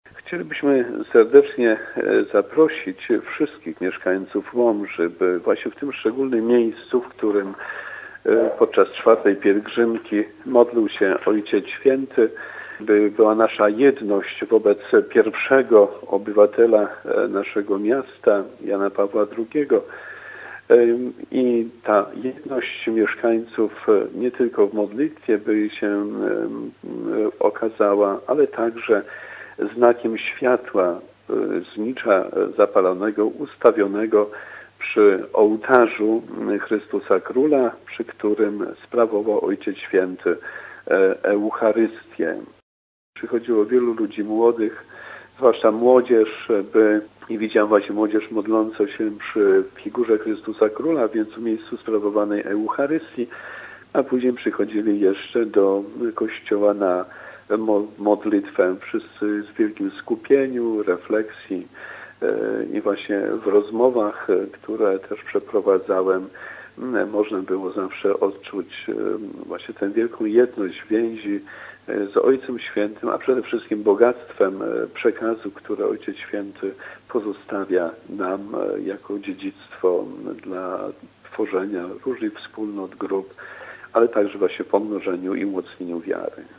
Ksiądz prałat apeluje o udział w modlitwie za papieża Jana Pawła II.